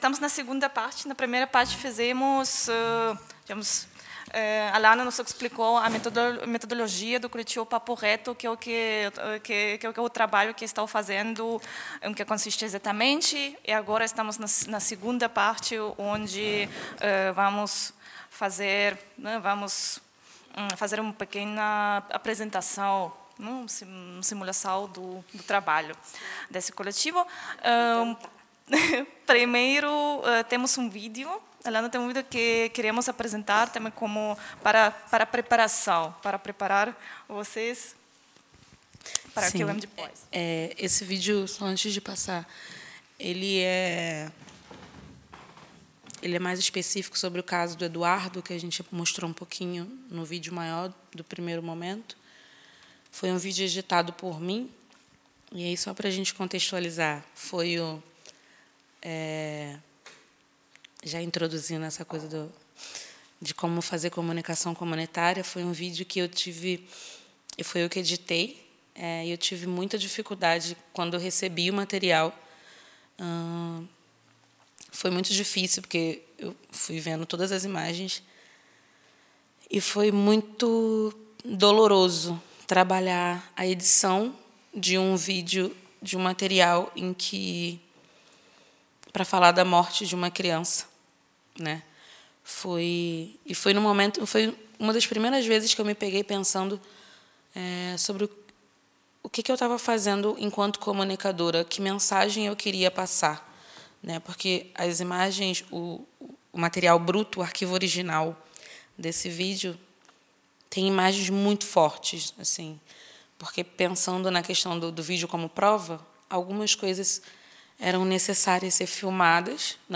Audio-Aufnahme des Forums | Download (mp3) [Teil 1] Audio-Aufnahme des Forums | Download (mp3) [Teil 2] Bilder: